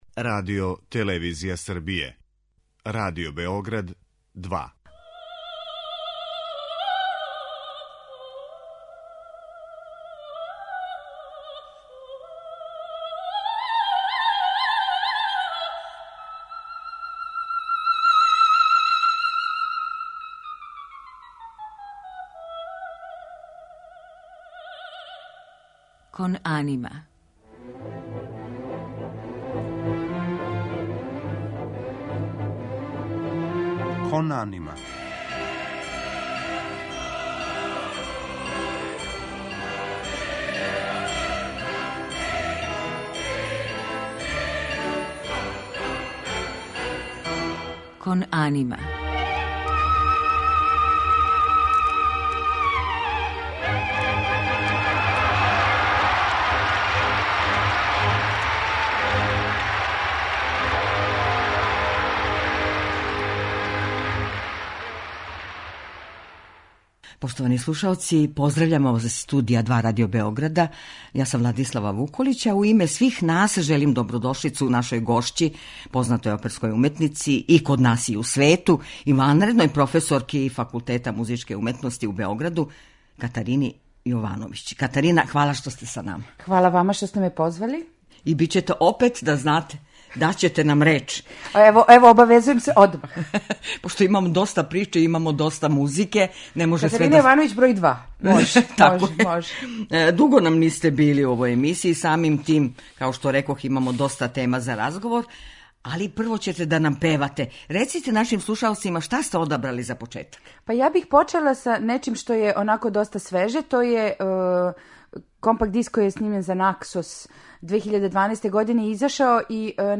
У музичком делу емисије биће емитоване арије и соло песме Жоржа Бизеа, Ђузепа Вердија, Рихарда Вагнера и Волфганга Амадеуса Моцарта, у њеном тумачењу.